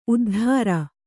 ♪ uddhāra